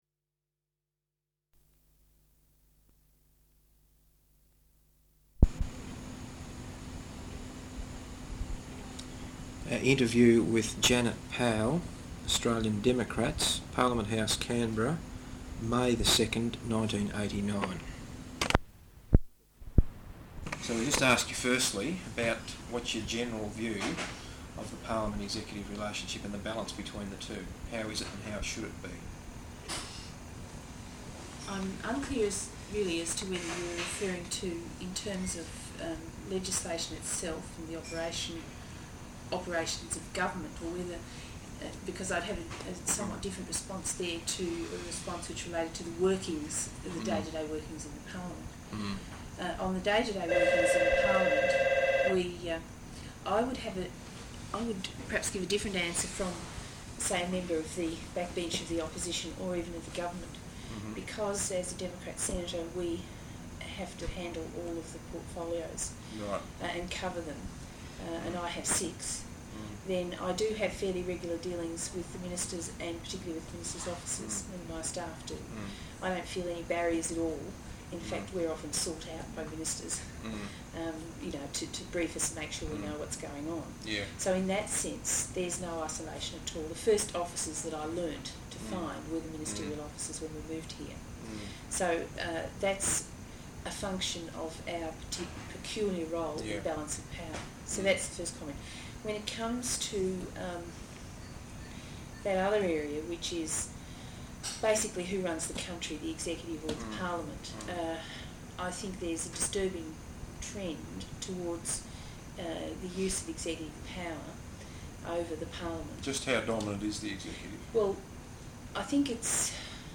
Interview with Janet Powell, Australian Democrats, Parliament House, Canberra, May 2nd 1989.